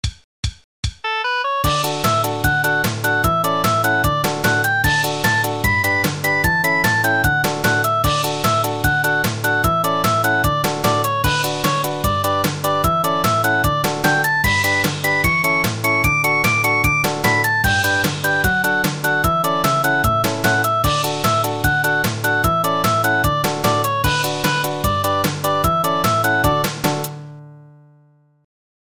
キャラクターが元気一杯画面を飛び回るイメージで
明るく楽しい曲ですので、様々なタイプのゲームにしっくり来るはずです。